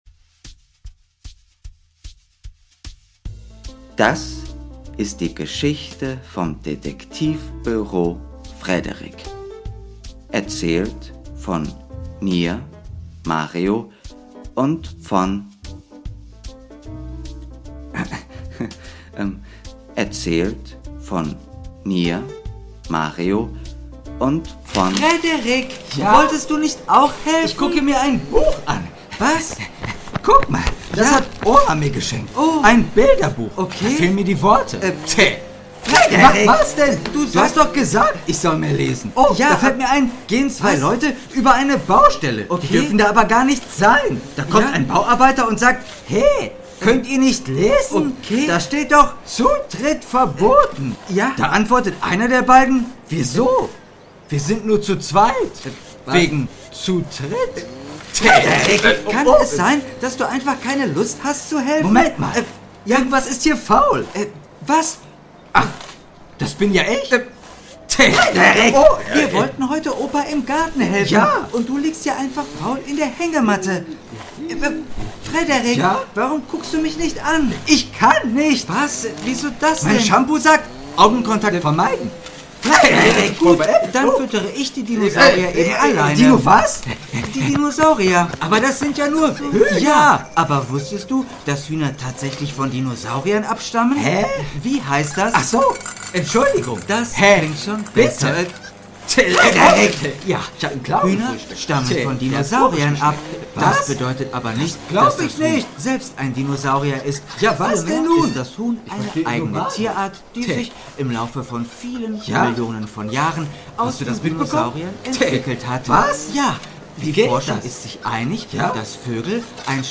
Kinderhörspiel